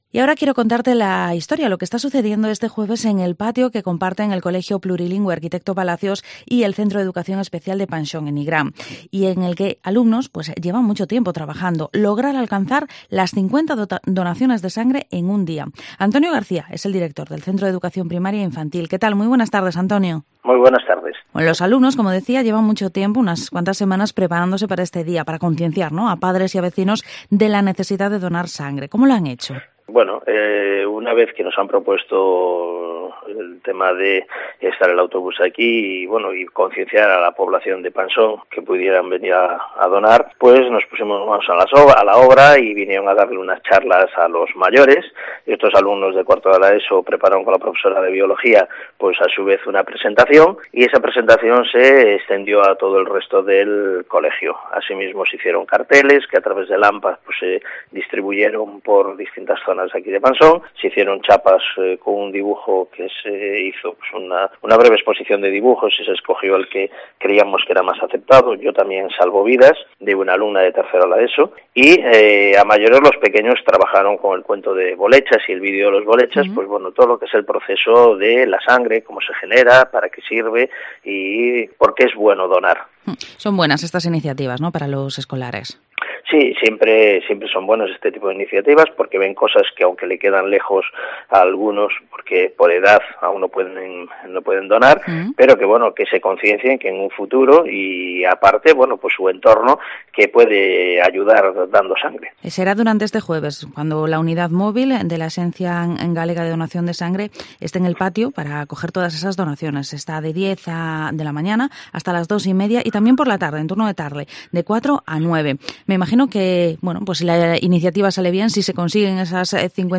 Área Metropolitana Vigo Entrevista 50 donaciones de sangre en un día.